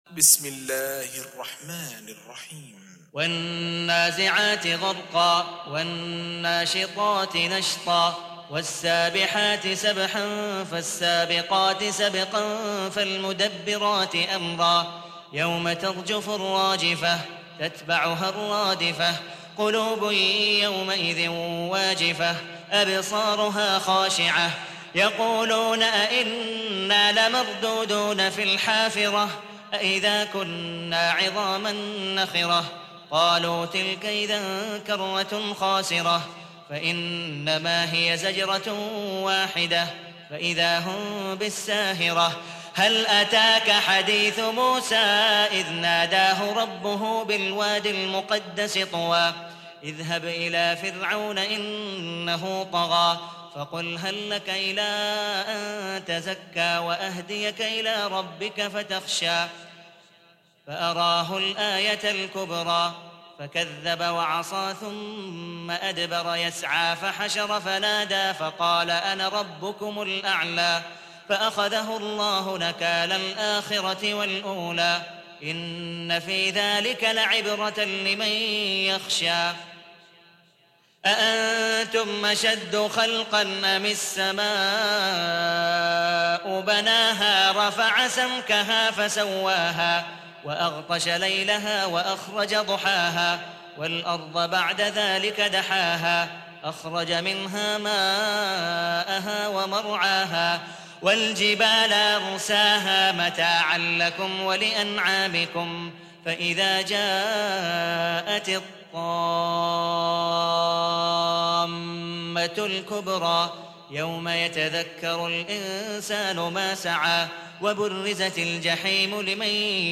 Surah Repeating تكرار السورة Download Surah حمّل السورة Reciting Murattalah Audio for 79.